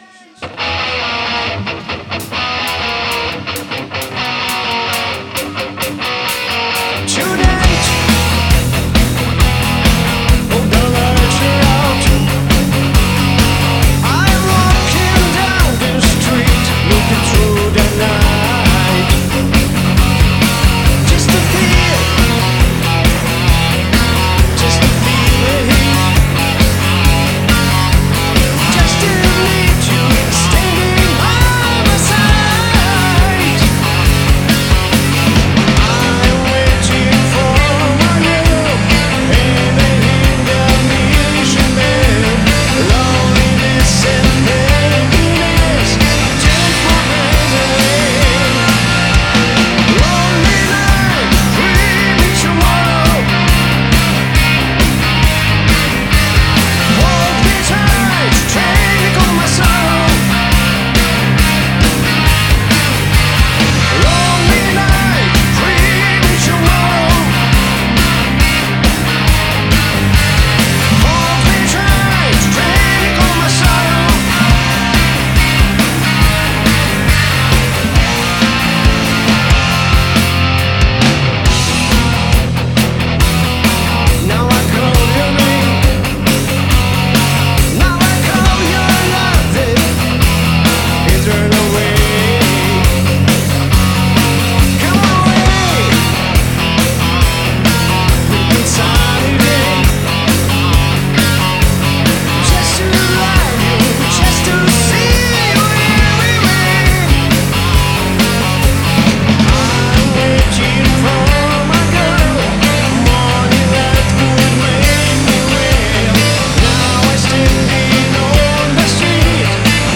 - звук в стиле классический хард-рок, никакого новодела.